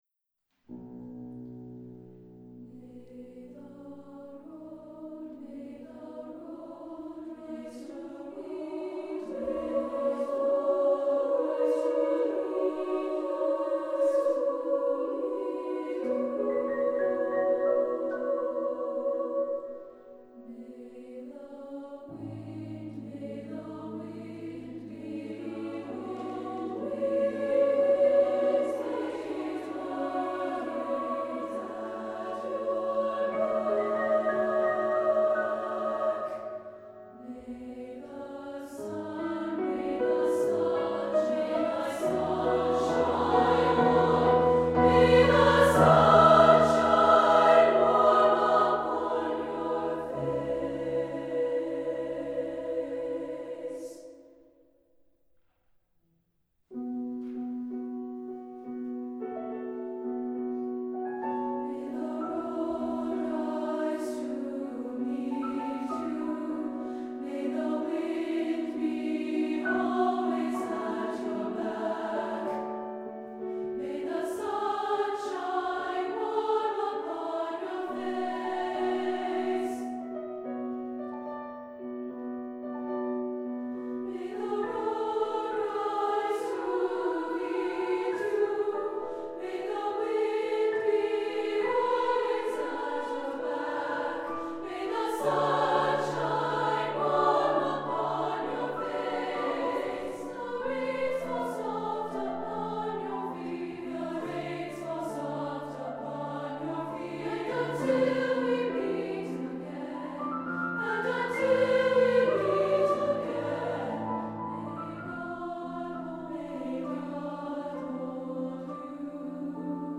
Voicing: SSSAA